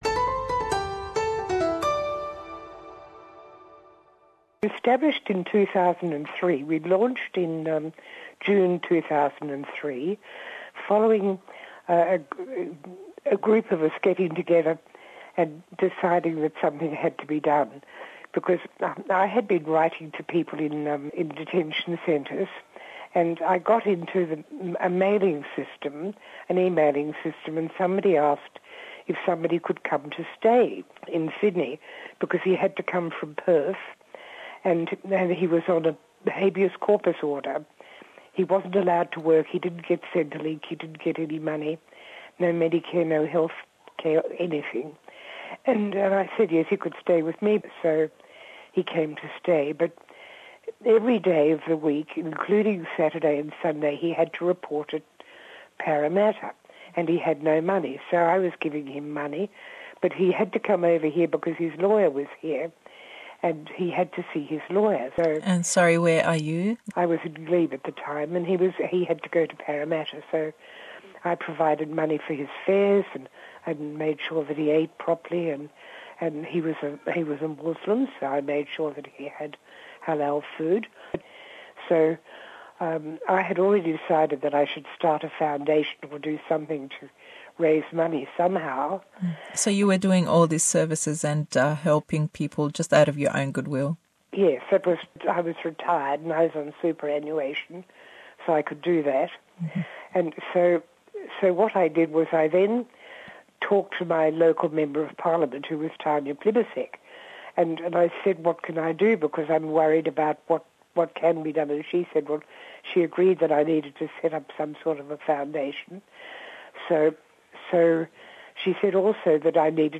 Le sallî 2003, rêkxiraweyekî damezran bo yarmetî ew penaxwazaney ke le ser visay katîn be nawî bridging visa, û ke çawerêy çarenûsiyan deken bo ewey be hemîsheyîbitwanin le australya bimêninewe. Em hevpeyvîne be zimanî Inglîzî ye: